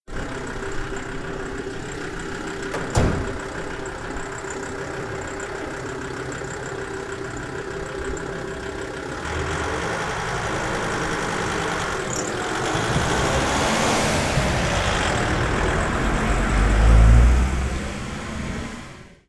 Truck is Leaving
A truck loads with goods and then leaves. Good Shotgun Recording.
Truckisleaving.mp3